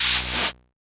SOUTHSIDE_percussion_ghosts_in_the_cab.wav